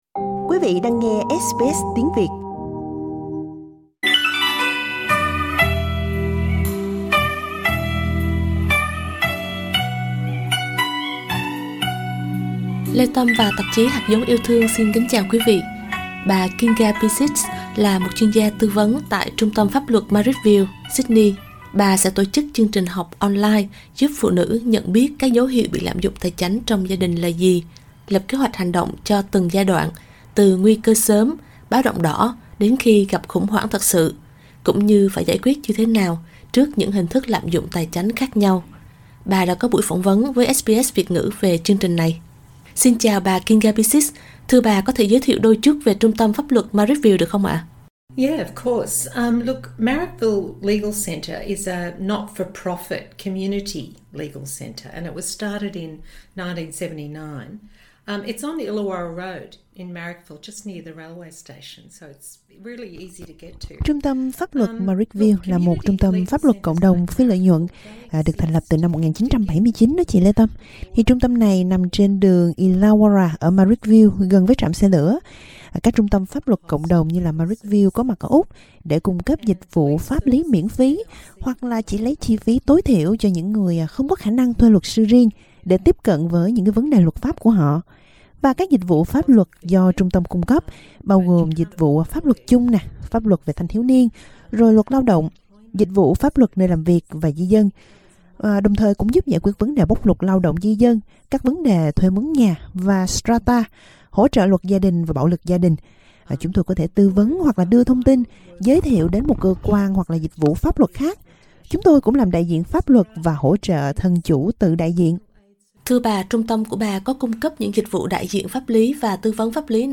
Trong buổi phỏng vấn với SBS Việt ngữ